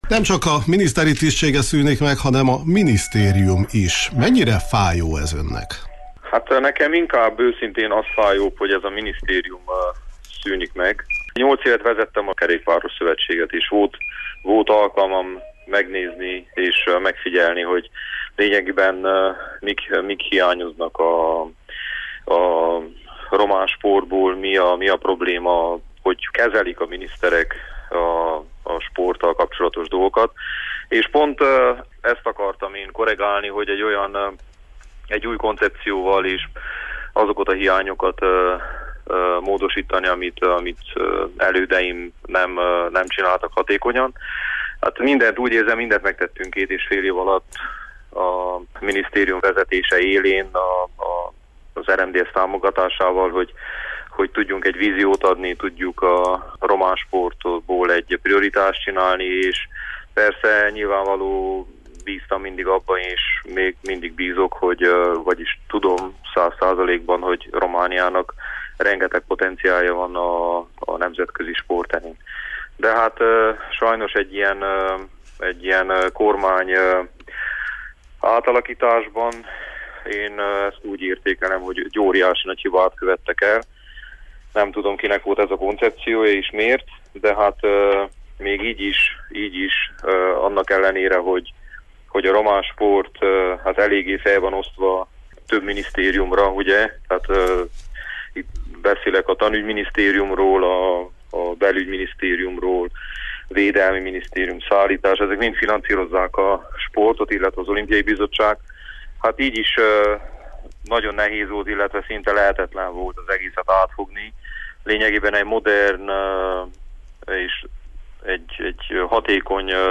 éppen pakolta össze a dolgait a minisztériumban, amikor a Kispad-ban utólérte őt telefonon